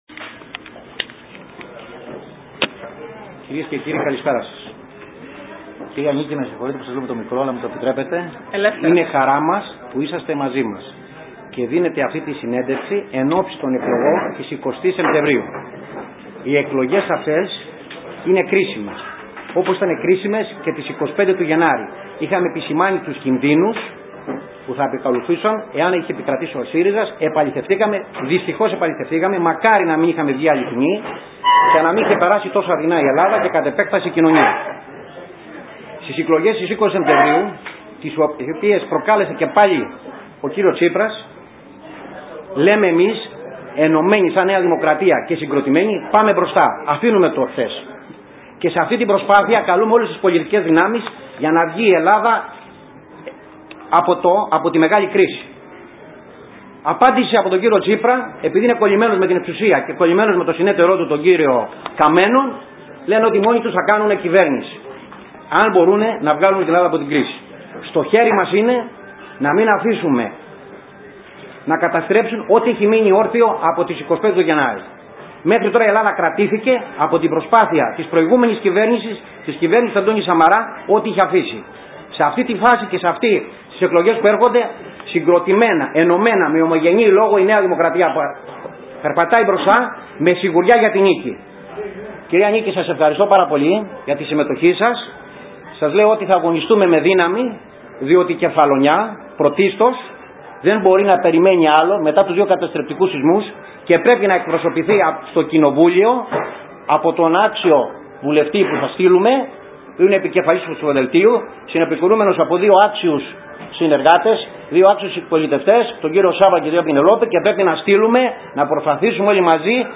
Συνέντευξη τύπου παραχώρησε η Βουλευτής Επικρατείας κ. Νίκη Κεραμέως υπεύθυνη Τομέα Διοικητικής Μεταρρύθμισης και Ηλεκτρονικής Διακυβέρνησης και υπεύθυνη Κοινοβουλευτικής κάλυψης Νομού Κεφαλονιάς της Νέας Δημοκρατίας.